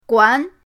guan2.mp3